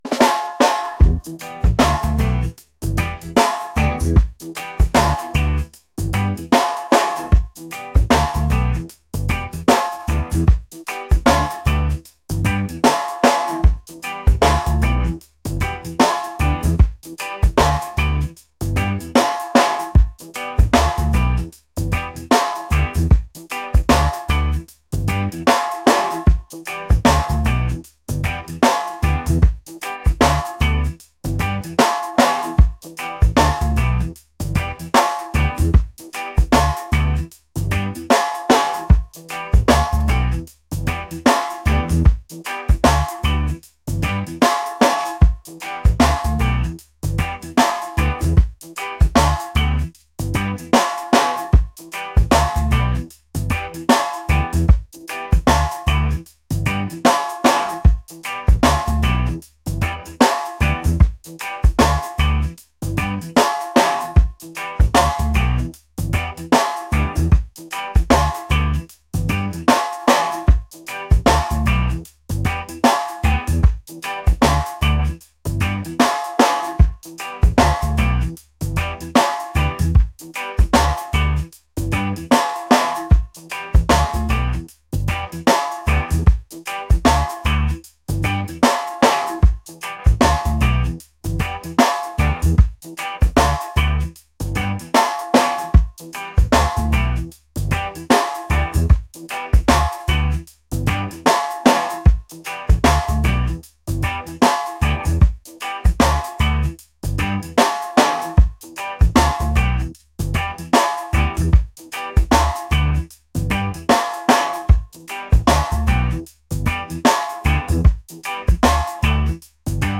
rhythmic | laid-back | reggae